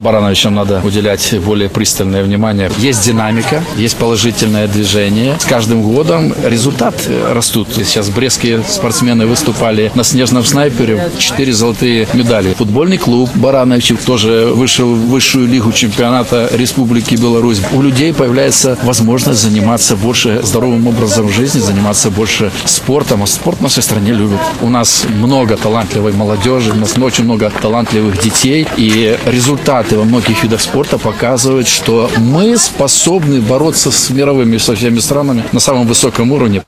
Барановичский регион обладает большим спортивным потенциалом и достоин более пристального внимания, — отметил министр спорта Сергей Ковальчук.